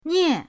nie4.mp3